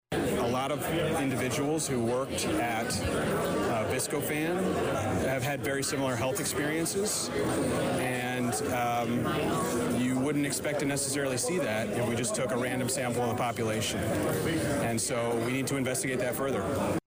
An overflow, standing room only crowd of several dozen filed into the Rabbittown Trade Center just off College Street in Danville Thursday night, as Hunterbrook Media hosted a community meeting concerning health and environmental issues involving Viscofan.